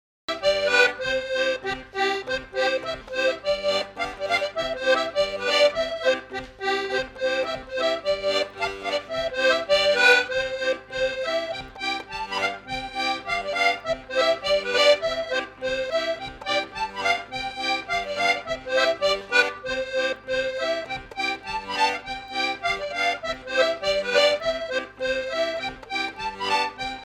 danse : branle : avant-deux
Fête de l'accordéon
Pièce musicale inédite